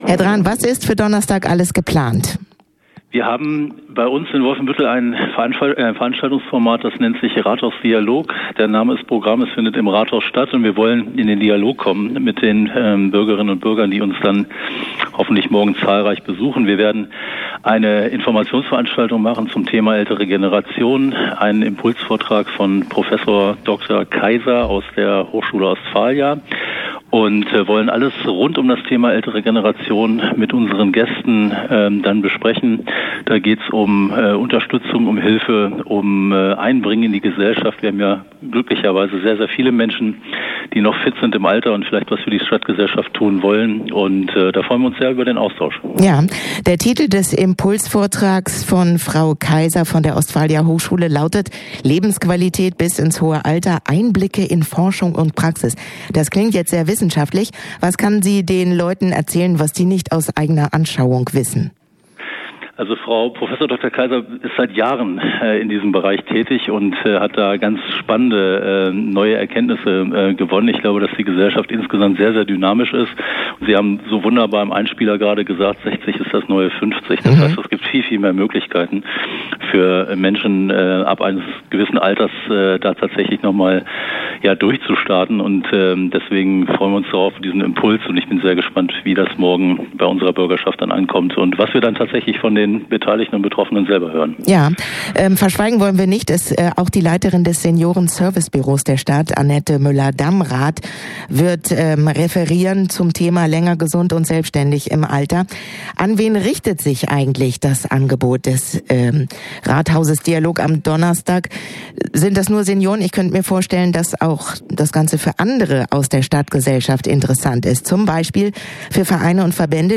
Interview-Rathausdialog-Drahn_nb.mp3